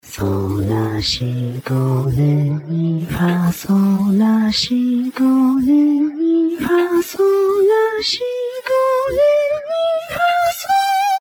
性別：少年のロボット
幸JPVCV_鬱（whisper）                        DL
収録音階：A3